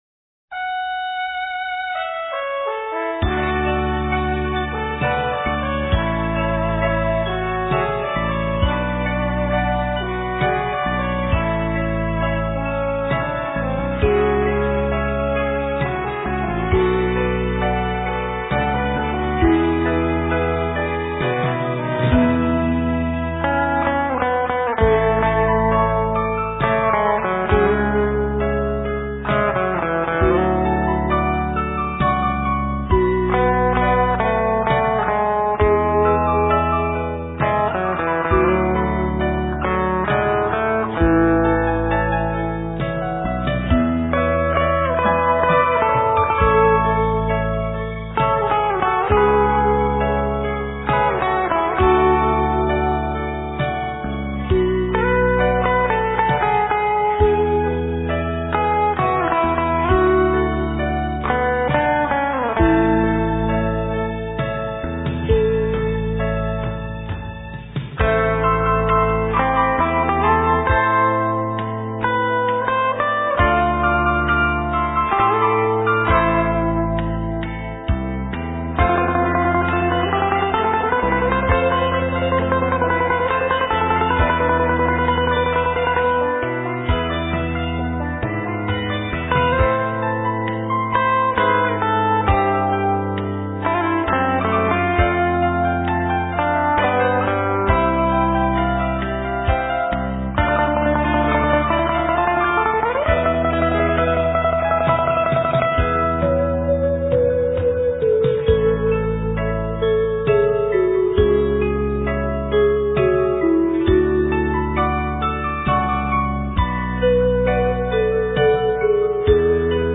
* Ca sĩ: Không lời
* Thể loại: Việt Nam